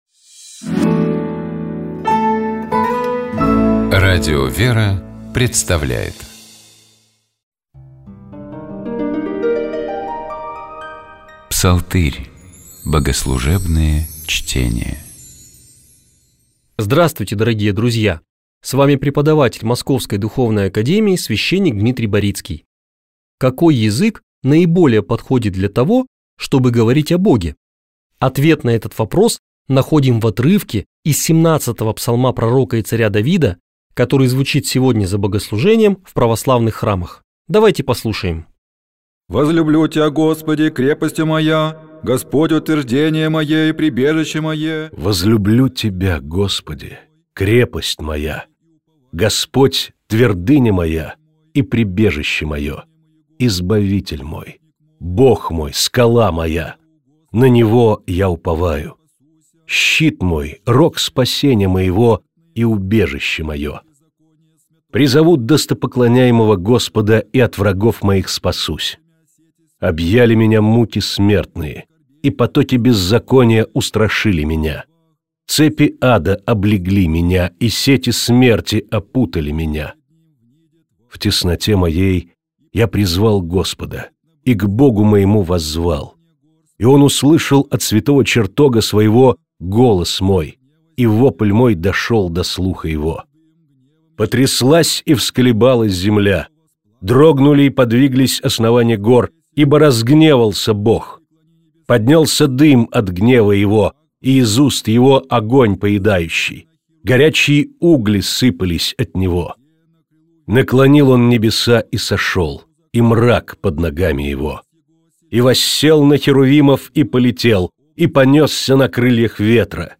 Псалом 17. Богослужебные чтения